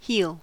Ääntäminen
IPA : /ˈhiːl/ IPA : /ˈhil/